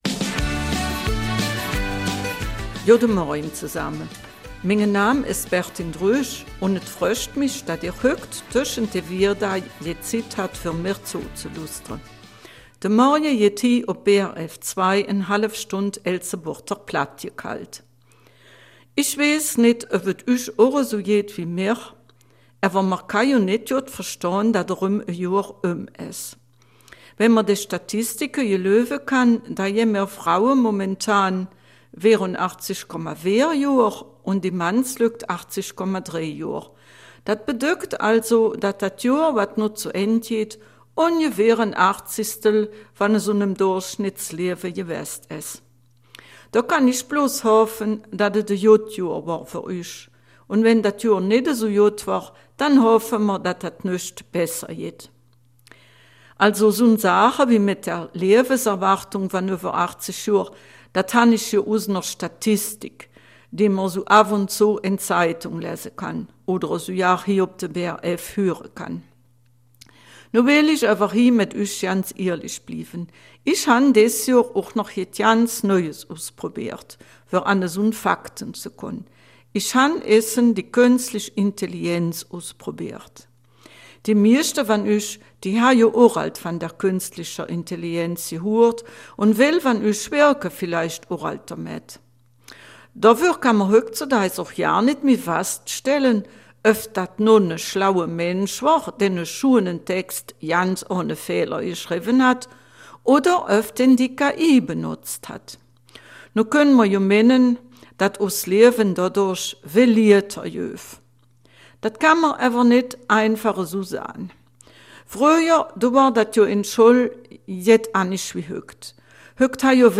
Eifeler Mundart - 28. Dezember